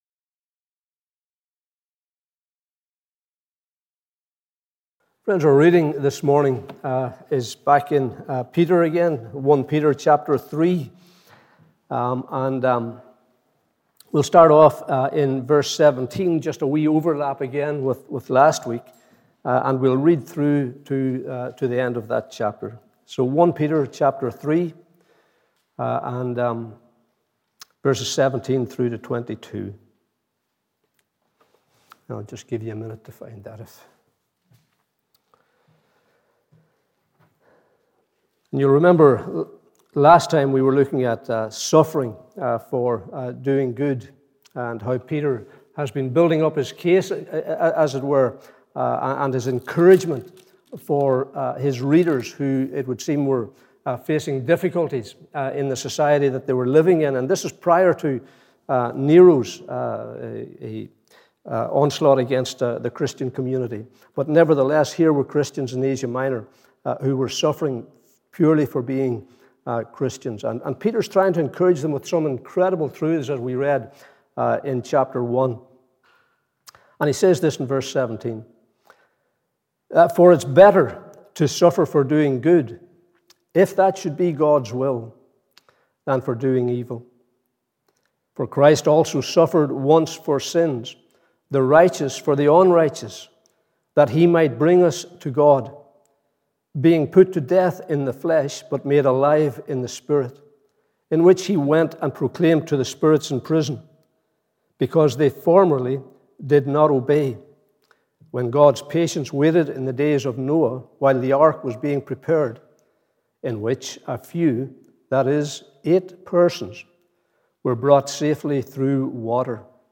Morning Service 22nd May 2022